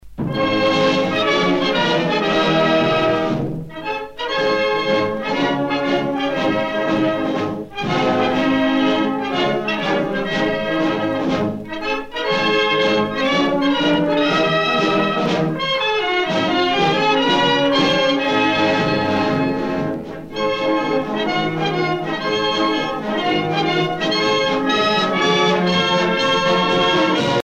gestuel : à marcher
circonstance : militaire
Pièce musicale éditée